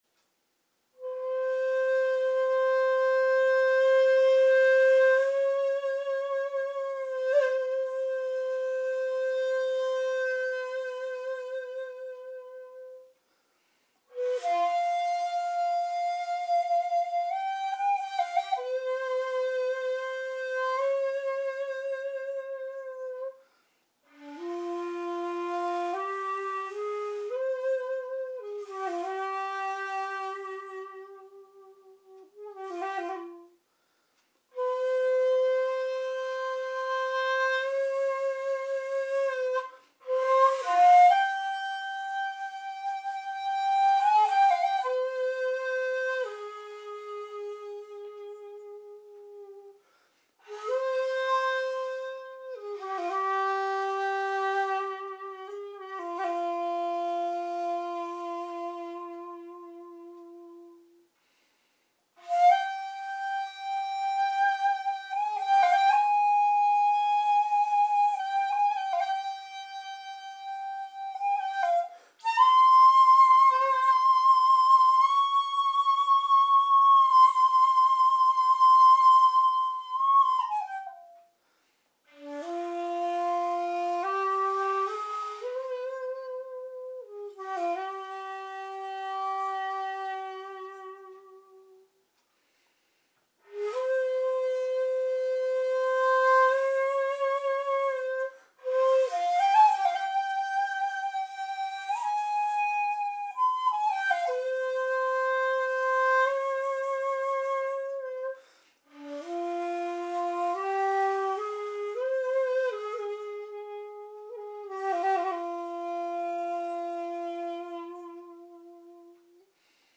まだまだ楽譜を見ながらの熟れない試奏ですがお聴き下さい。